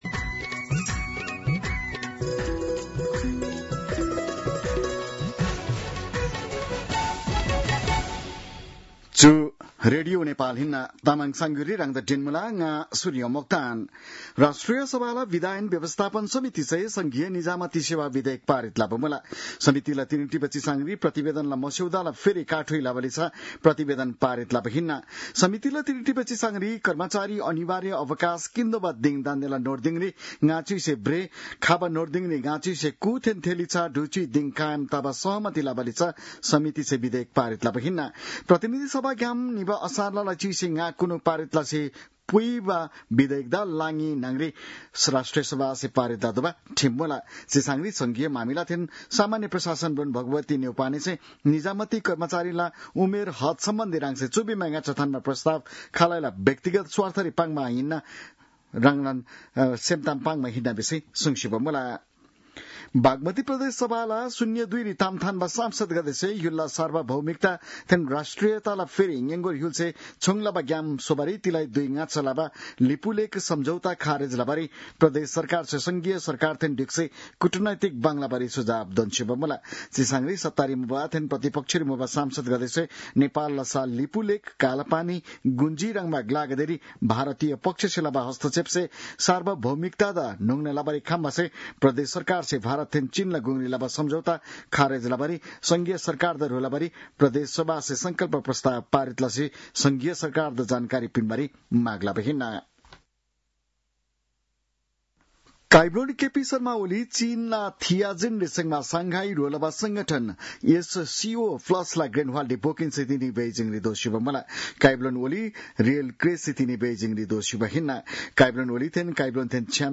तामाङ भाषाको समाचार : १७ भदौ , २०८२